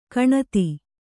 ♪ kaṇati